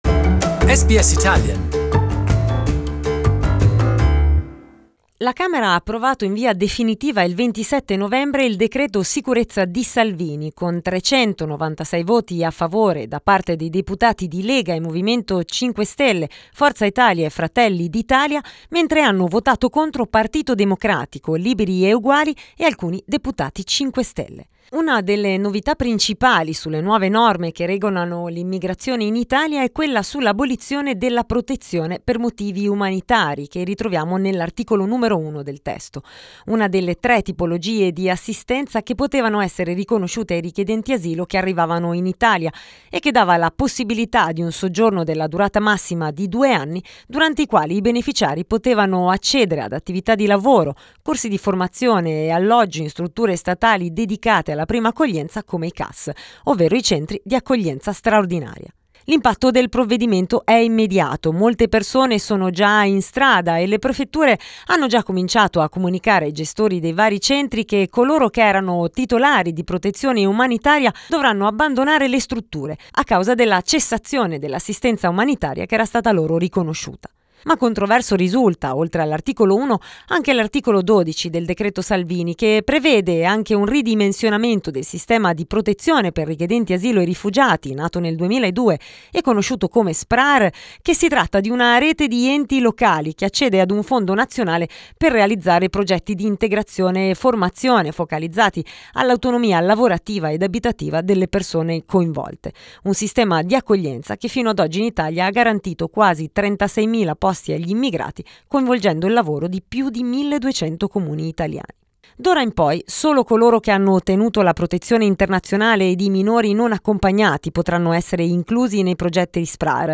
SBS Italian